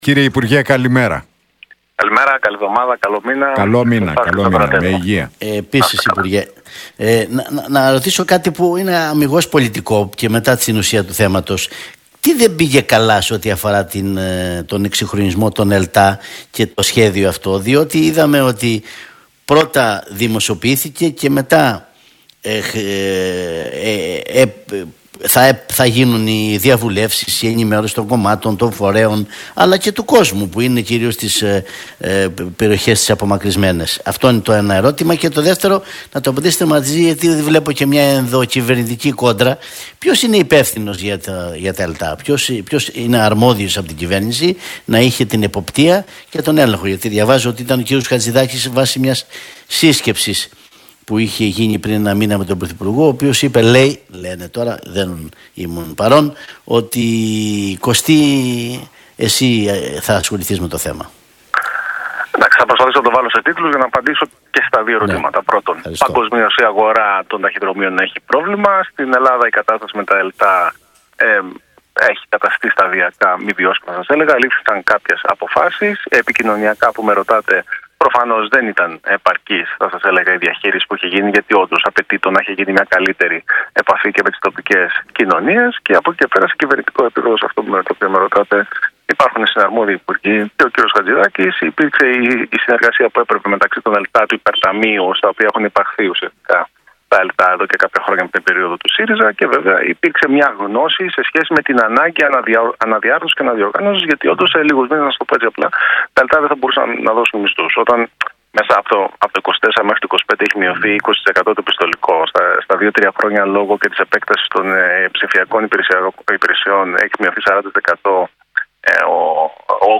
Κοντογεώργης στον Realfm 97,8 για ΕΛΤΑ: Προφανώς δεν ήταν επαρκής η διαχείριση επικοινωνιακά